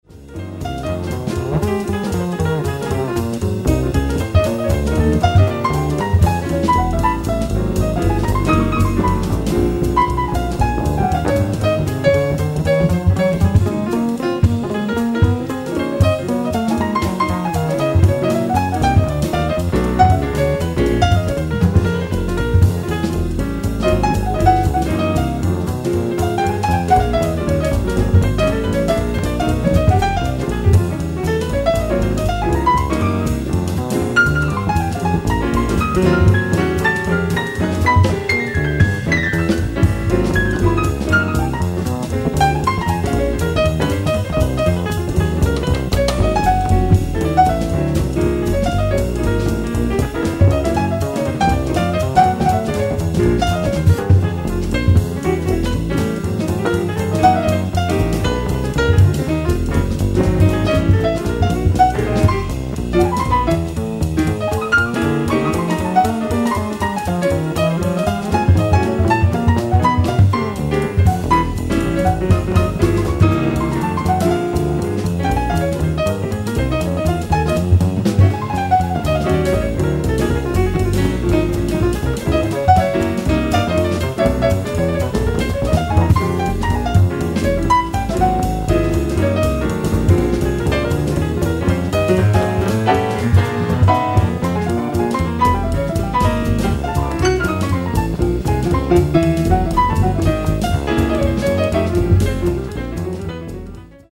ディスク１：ライブ・アット・モントルー・ジャズ、スイス 07/17/1990
※試聴用に実際より音質を落としています。